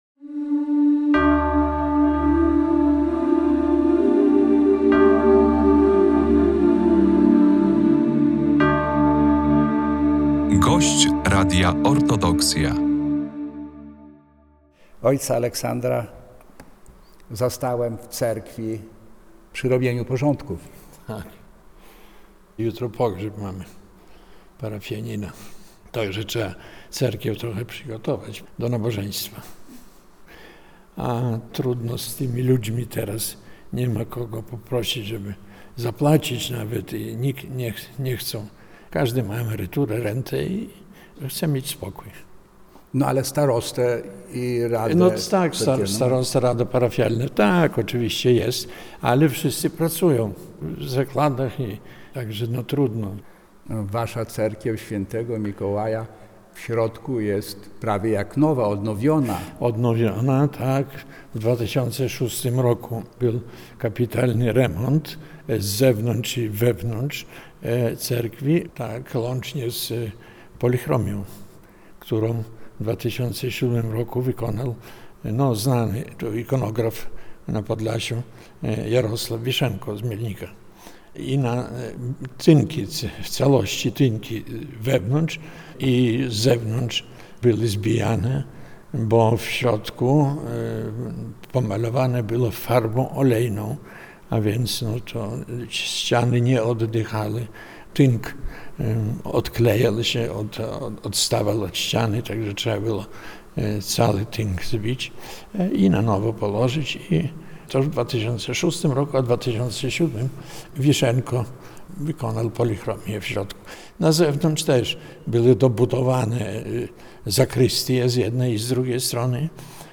A kiedyś to było… rozmowy o życiu i Cerkwi – to cykl audycji radiowych z prawosławnymi duchownymi, którzy tworzyli historię Polskiego Autokefalicznego Kościoła Prawosławnego.